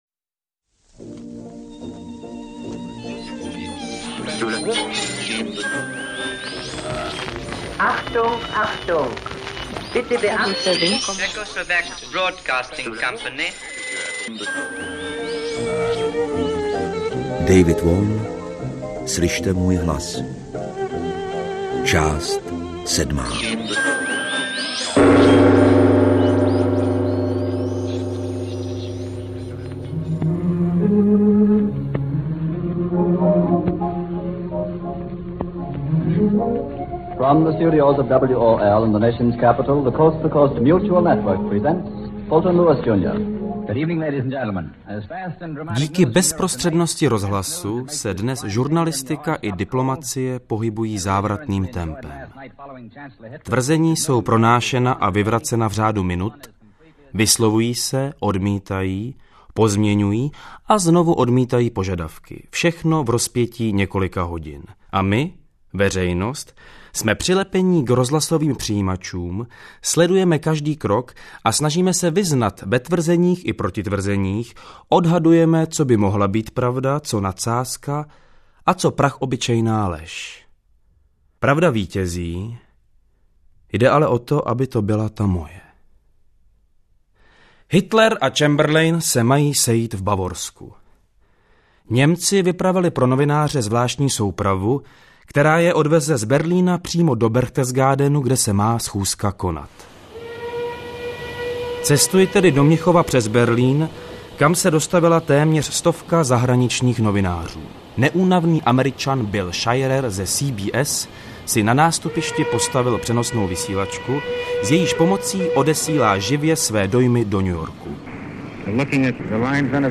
Drama z prostředí Prahy během mnichovské krize s Václavem Neužilem v hlavní roli.